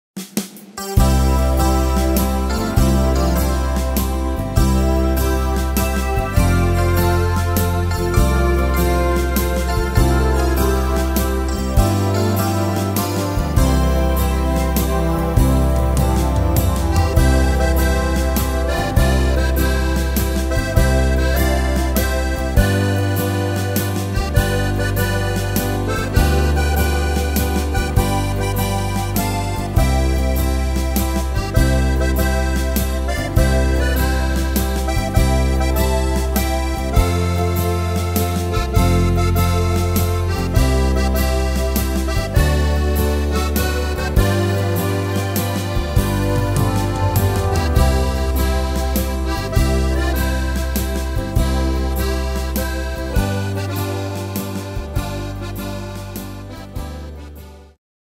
Tempo: 100 / Tonart: C-Dur